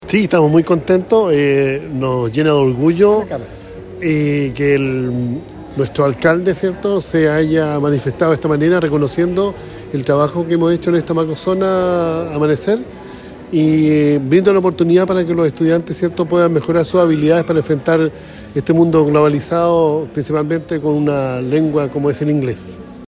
Con una ceremonia realizada en el patio techado del Complejo Educacional Amanecer, Temuco dio inicio oficial al año escolar 2025, declarado como el «Año de las Ciencias y la Tecnología» para la educación pública de la comuna.